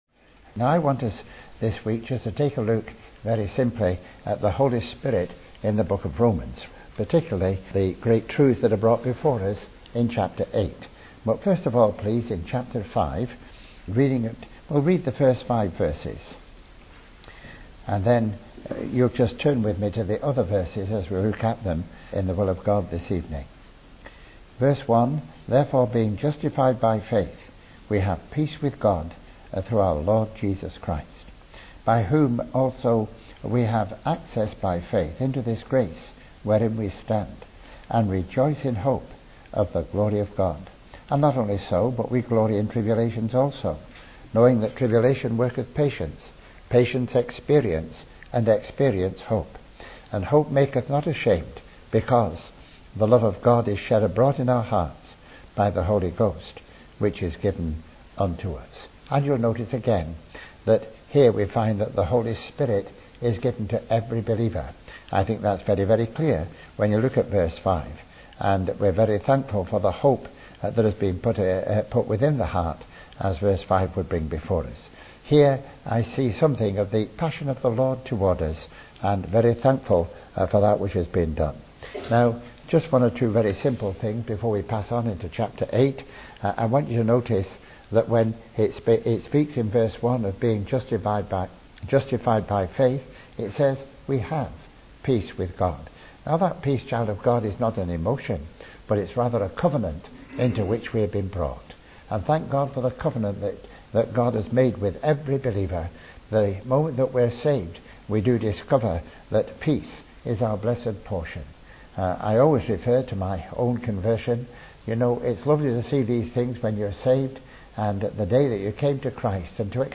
Starting in Chapter 5 and concluding in Chapter 8, he develops the teaching of Paul with regard to the Holy Spirit being the power for Christian living and the One who bears witness with our spirits that we are the children of God – and if children, then heirs! (Message preached 4th Dec 2014)